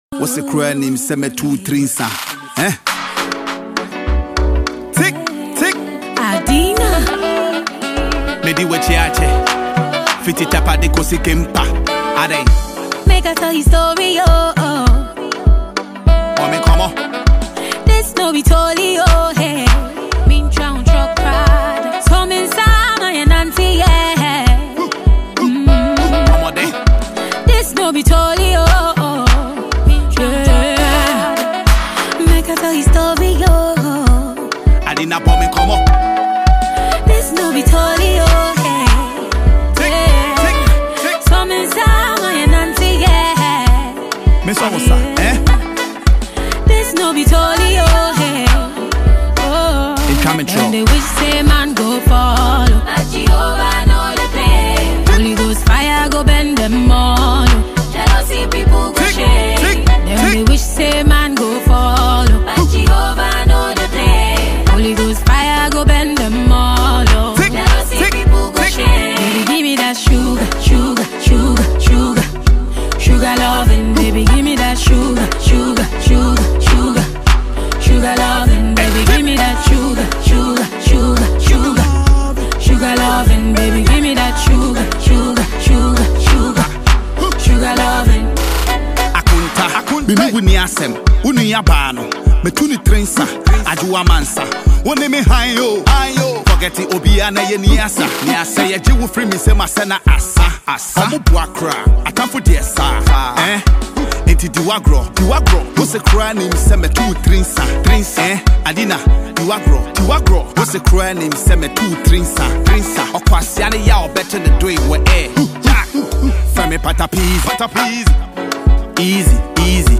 female singer